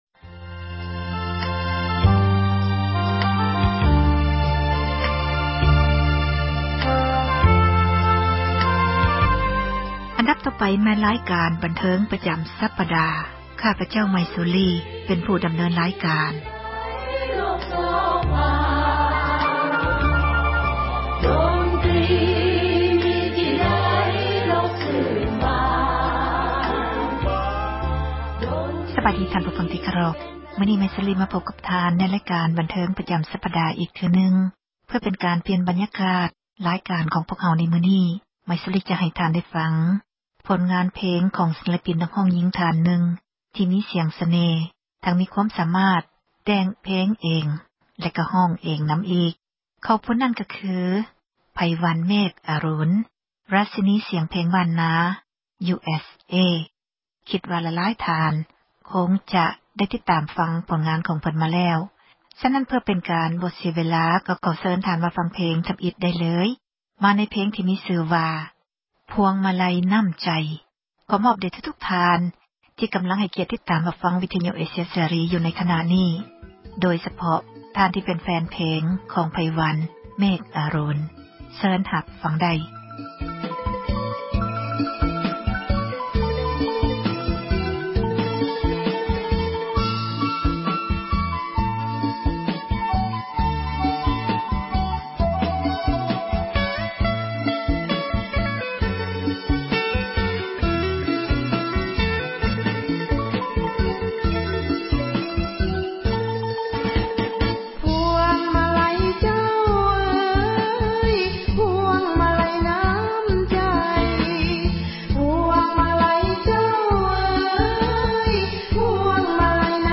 ດົນຕຣີເພງລາວ ສມັຍ-ສາກົນ 07/13/2013